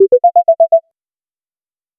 niche notification sound for dating app, The sound effect or app starting sound need to be very attractive and needs to stick with user subconscious mind like Netflix / tinder and PhonePe did 0:02 Created Jun 15, 2025 9:21 PM
niche-notification-sound--pgisnbna.wav